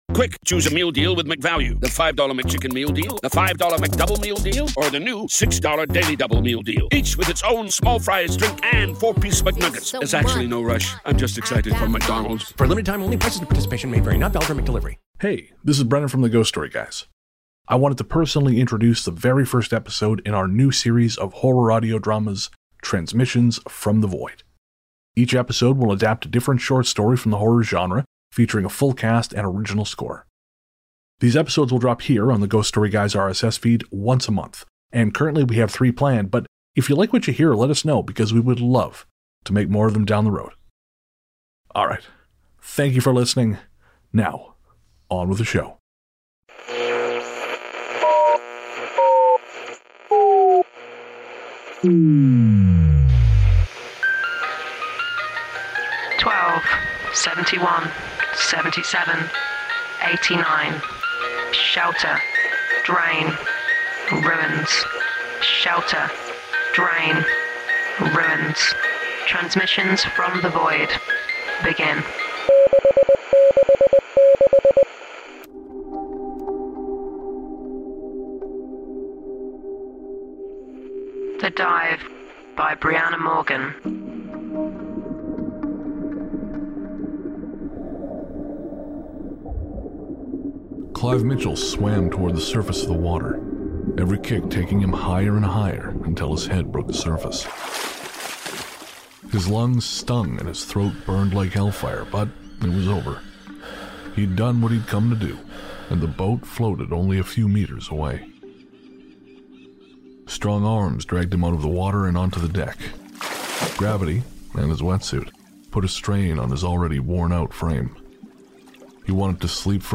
The Dive: A Ghost Story Guys Audio Drama